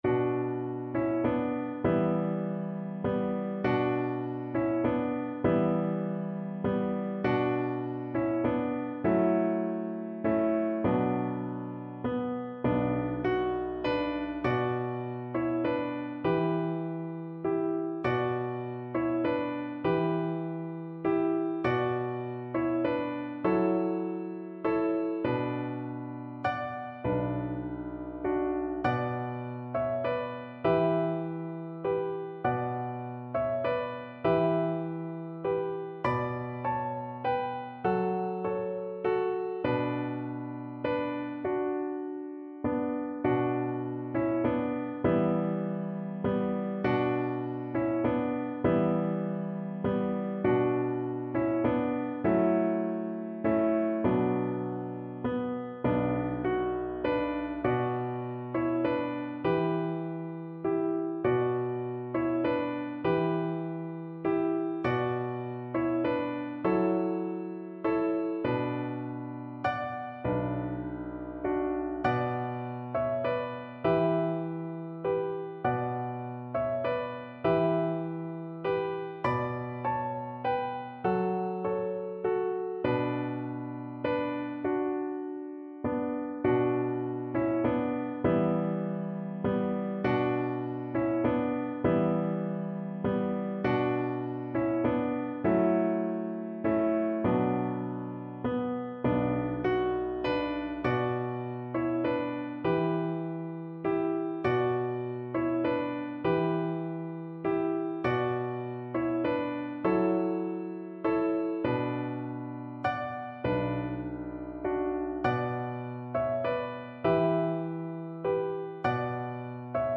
Free Sheet music for Choir (3 Voices)
Voice 1Voice 2Voice 3
6/4 (View more 6/4 Music)
Classical (View more Classical Choir Music)